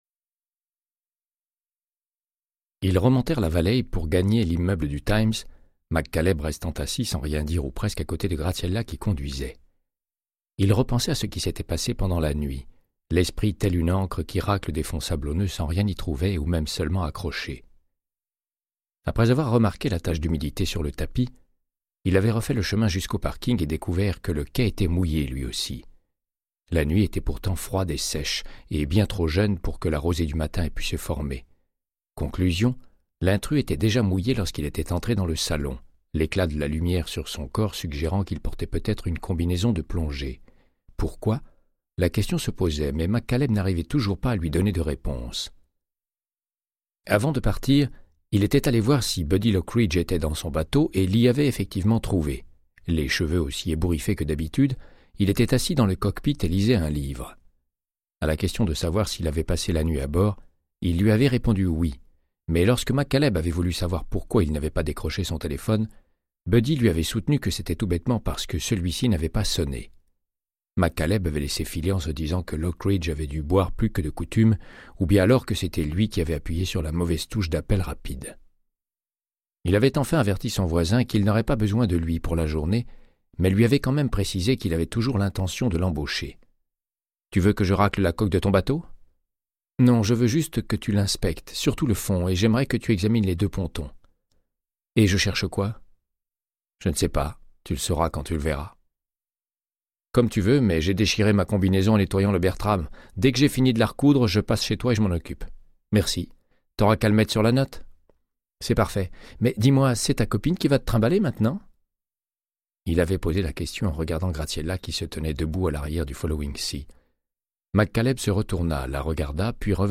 Audiobook = Créance de sang, de Michael Connelly - 109